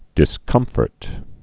(dĭs-kŭmfərt)